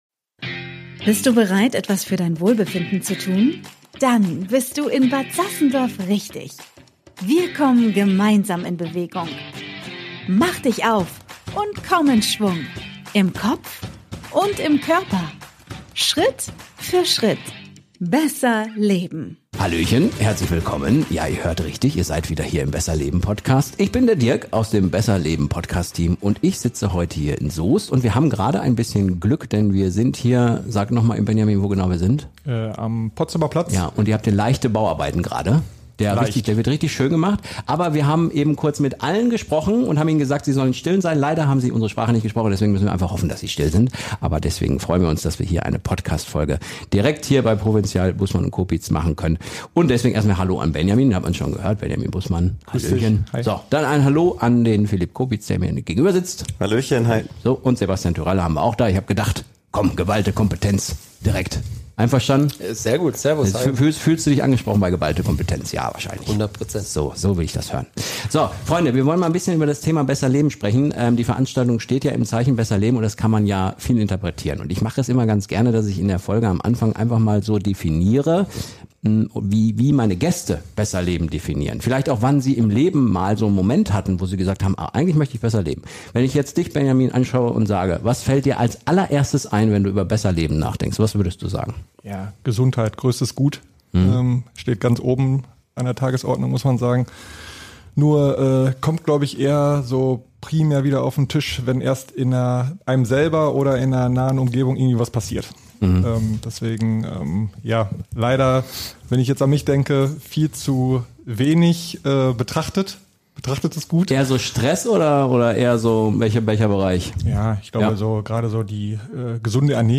Die Runde zeigt, wie Mindset, Bewegung und kleine Veränderungen im Alltag den Unterschied machen können.